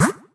BackCancelSound.wav